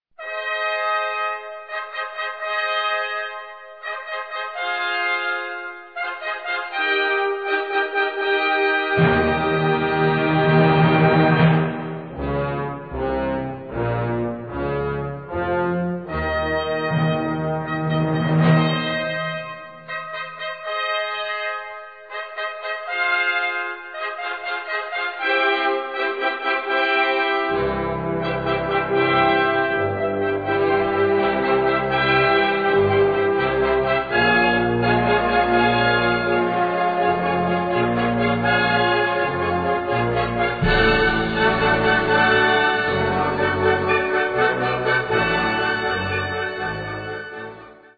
Kategorie Blasorchester/HaFaBra
Unterkategorie Ouvertüre, bearbeitet
Besetzung Ha (Blasorchester)